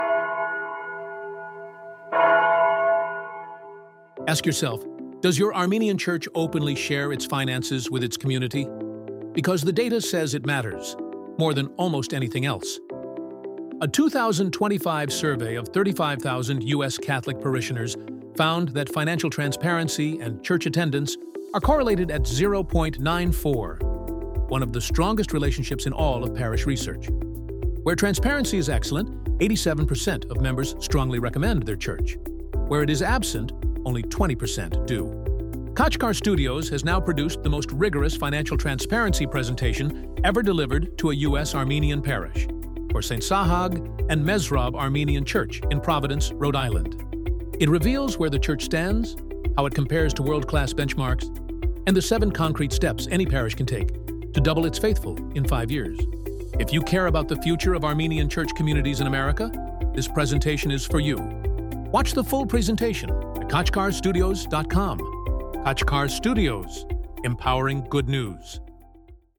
Sts. Sahag & Mesrob Armenian Church: 60-second Commercial – Audio